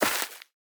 Minecraft Version Minecraft Version snapshot Latest Release | Latest Snapshot snapshot / assets / minecraft / sounds / block / rooted_dirt / step1.ogg Compare With Compare With Latest Release | Latest Snapshot
step1.ogg